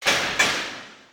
key_collect.ogg